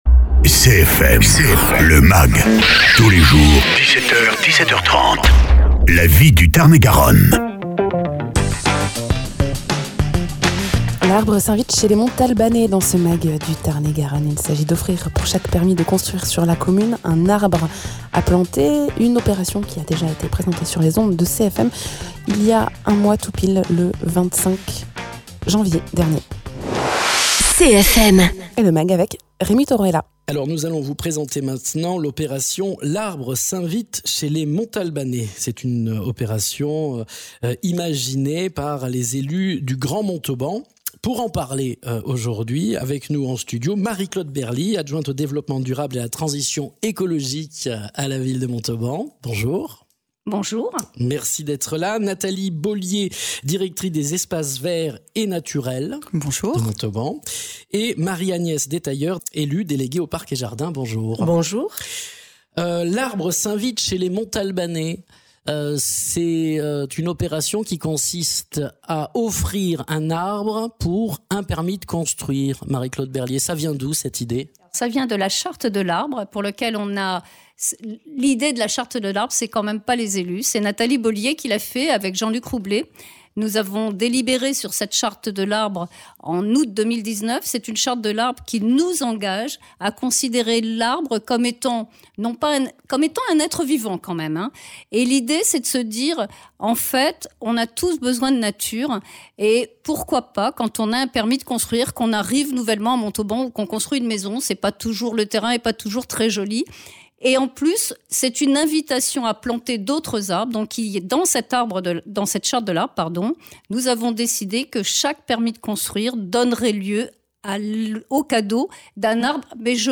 Invité(s) : Marie Claude Berly, adjointe au développement durable et à la transition écologique ; Marie Agnes Detailleur, élue déléguée aux parc et jardins